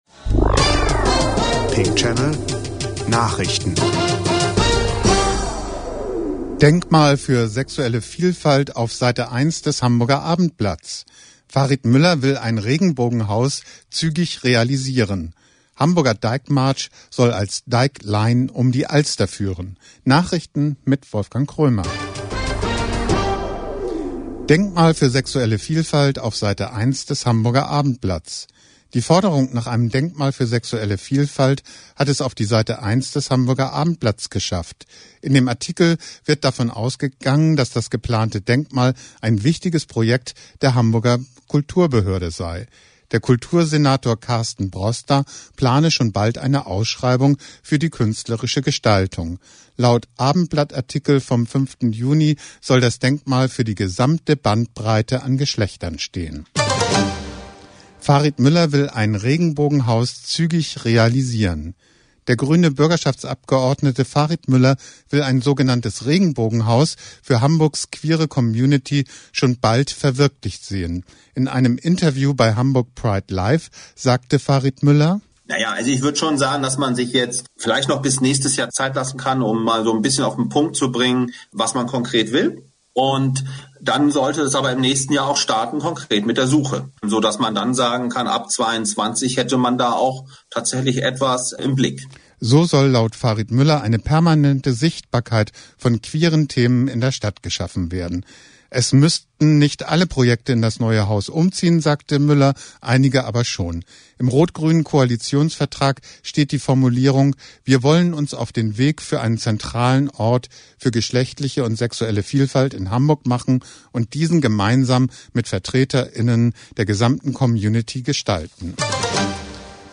Pink Channel Nachrichten 06.06.2020